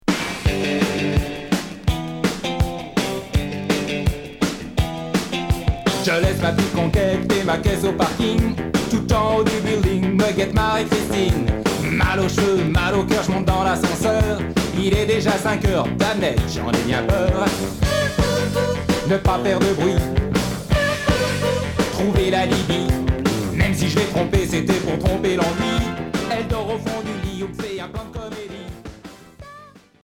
Rock new wave